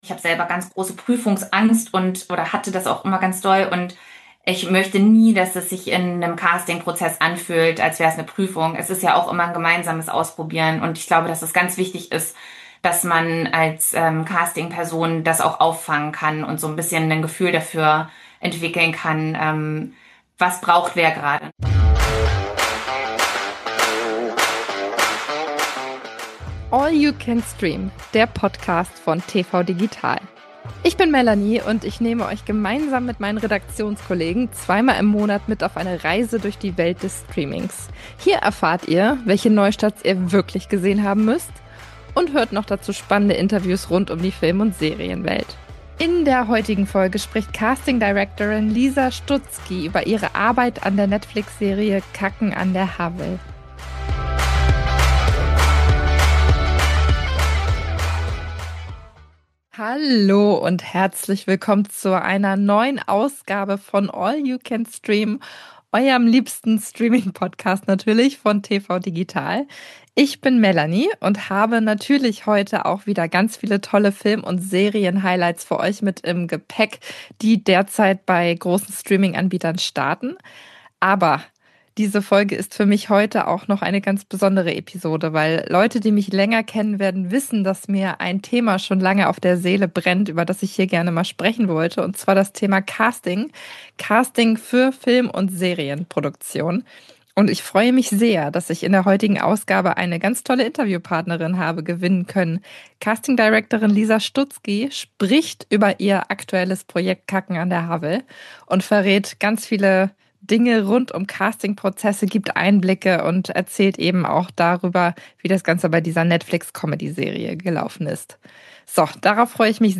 Exklusives Interview